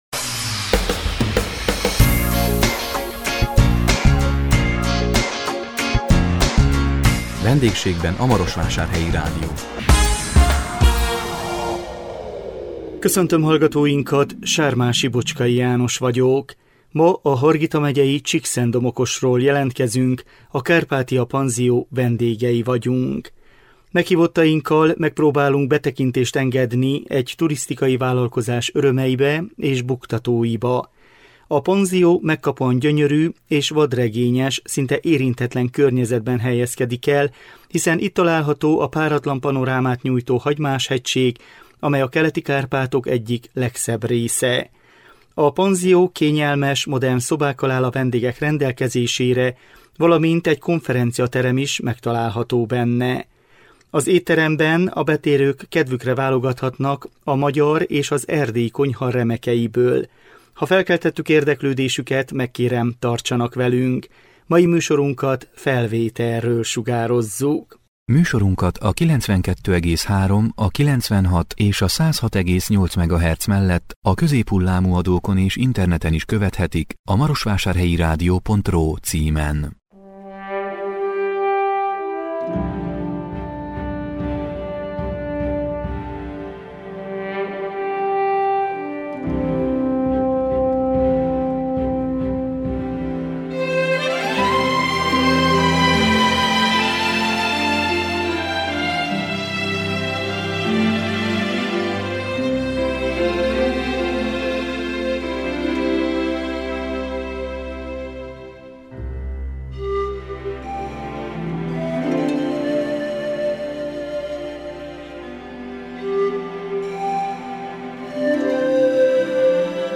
A 2021 december 2-án jelentkező VENDÉGSÉGBEN A MAROSVÁSÁRHELYI RÁDIÓ című műsorunkkal a Hargita megyei Csíkszentdomokosról jelentkeztünk, a Kárpátia Panzió vendégei voltunk. Meghívottainkkal megpróbáltunk betekintést engedni egy turisztikai vállalkozás örömeibe és buktatóiba.